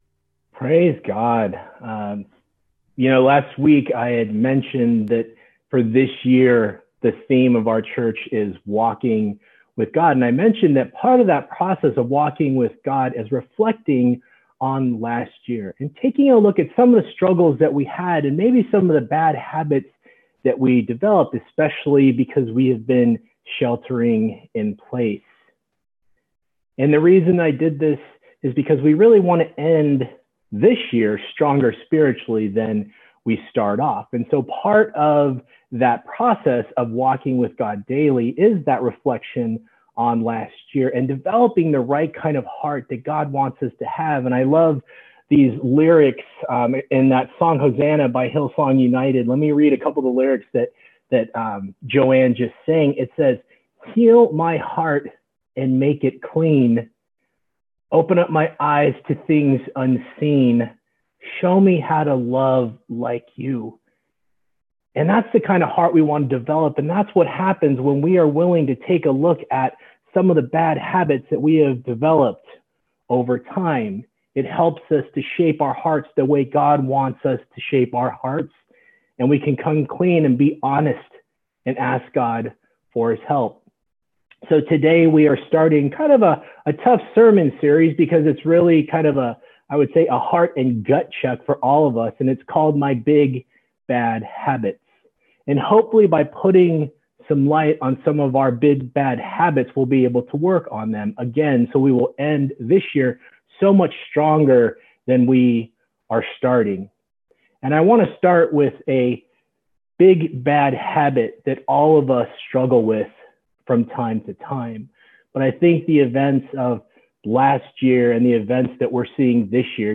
January 17, 2021 Sunday Message